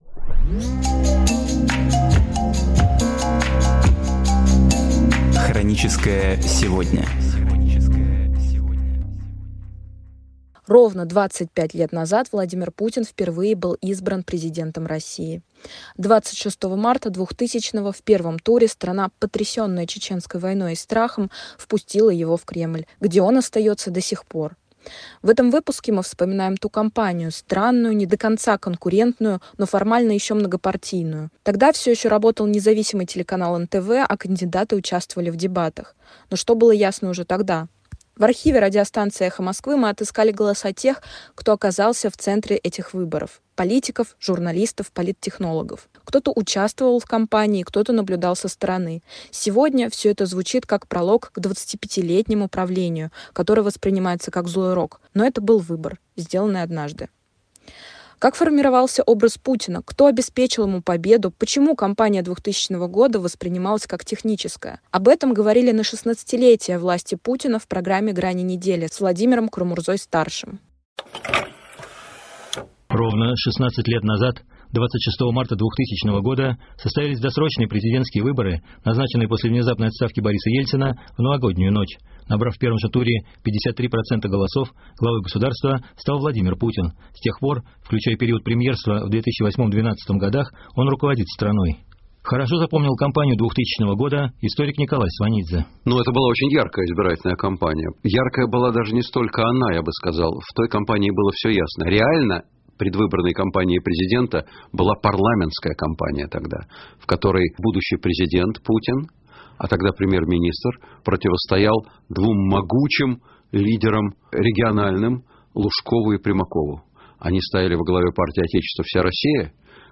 Архивные передачи «Эха Москвы» на самые важные темы дня сегодняшнего